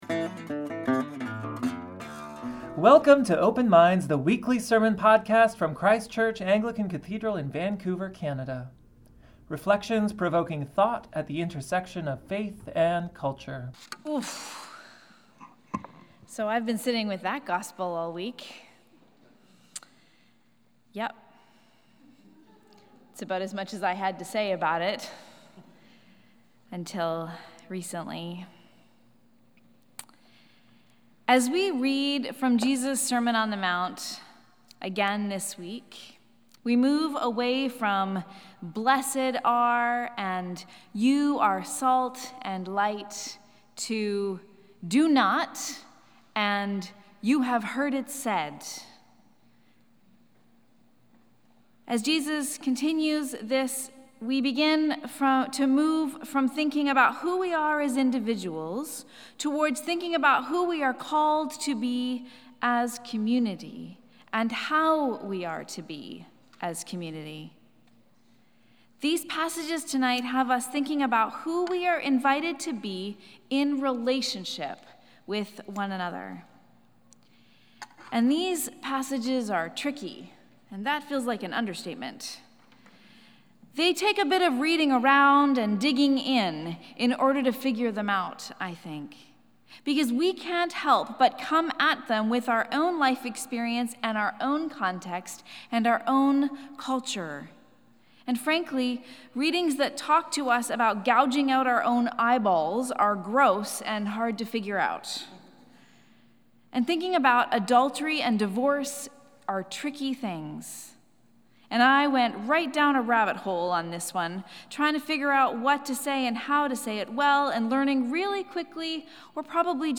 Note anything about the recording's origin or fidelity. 5.30pm St. Brigid's Eucharist